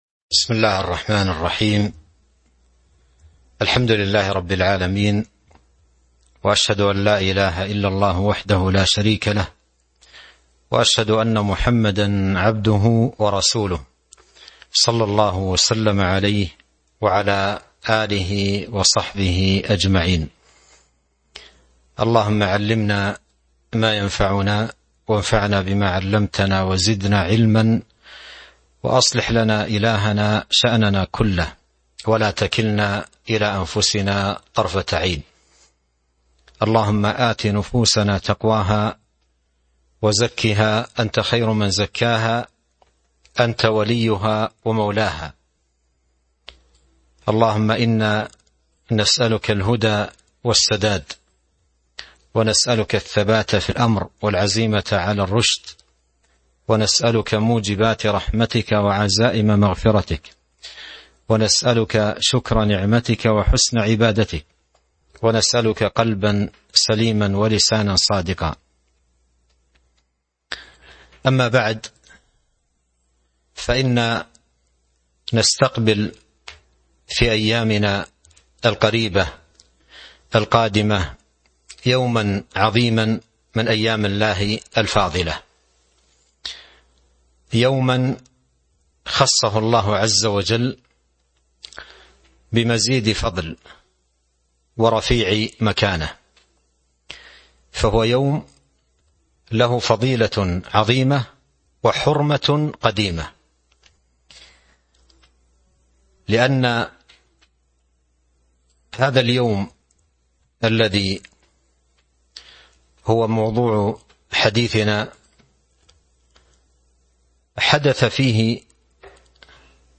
تاريخ النشر ٧ محرم ١٤٤٣ هـ المكان: المسجد النبوي الشيخ: فضيلة الشيخ عبد الرزاق بن عبد المحسن البدر فضيلة الشيخ عبد الرزاق بن عبد المحسن البدر يوم عاشوراء